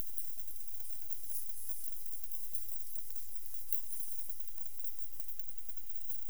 Data resource Xeno-canto - Chiroptera sounds from around the world